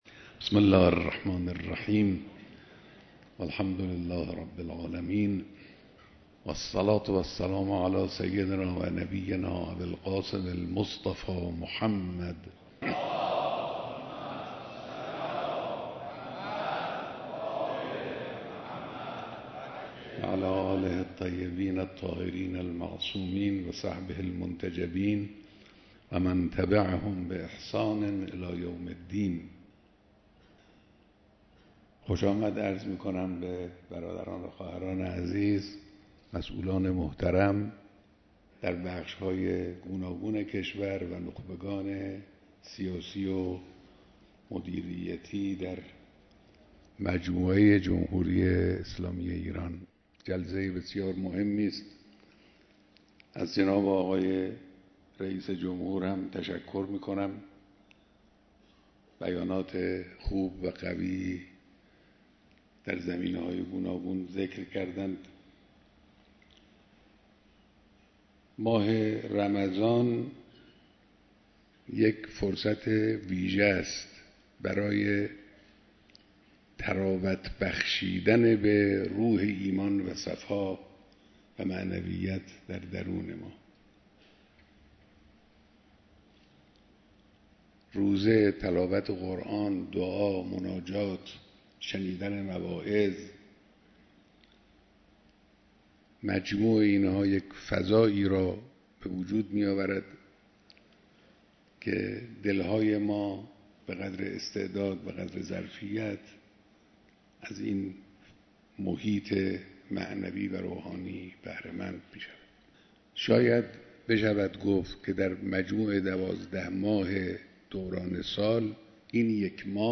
بیانات در دیدار مسئولان نظام